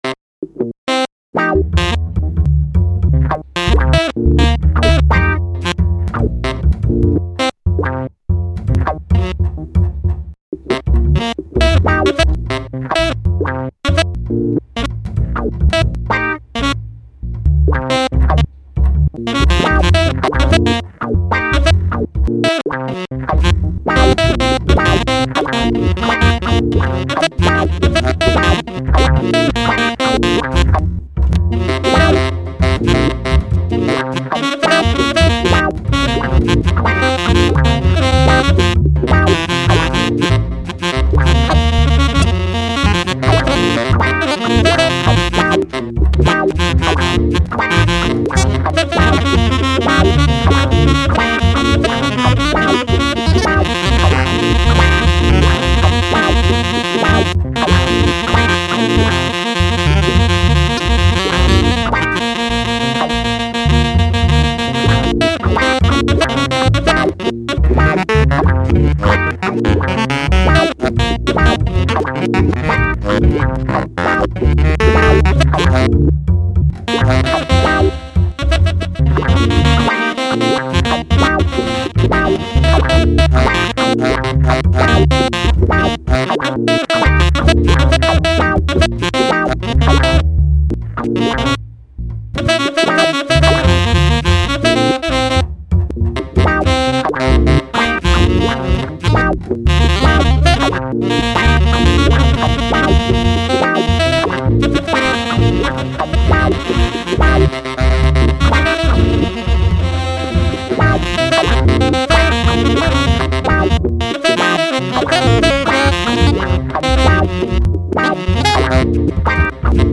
mixed with this new version of Girl
freejazz.mp3